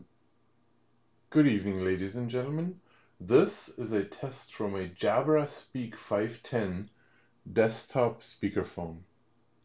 Jabra 510USB/bluetooth desktop conference speaker/microphone
jabra-speak-510-speakerphone.m4a